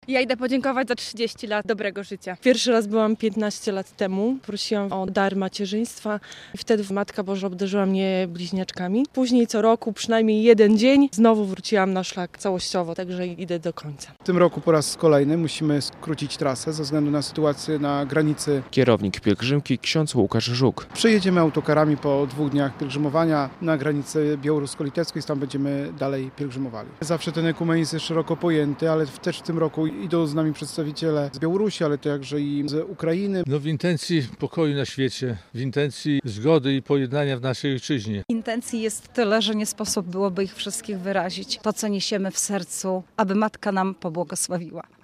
Cały nasz bagaż i jeszcze więcej - mówi jeden z pielgrzymów.